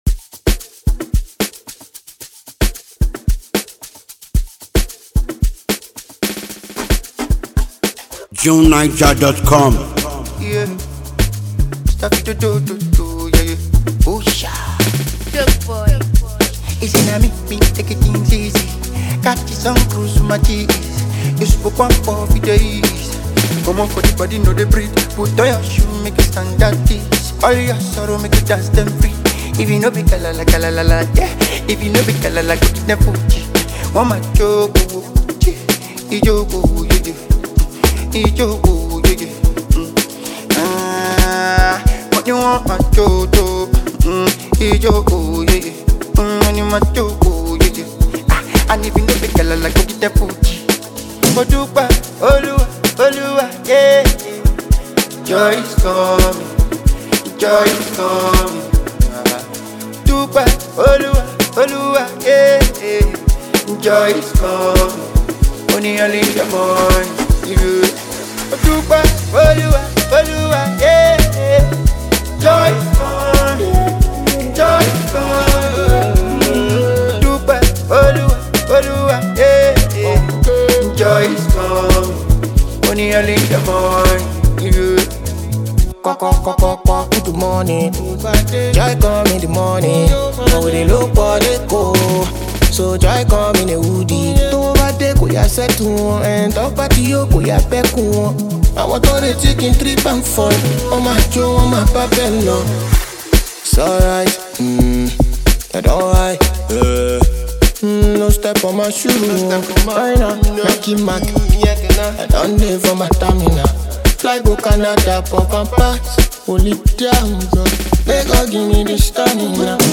throbbing rendition of his recent number-one single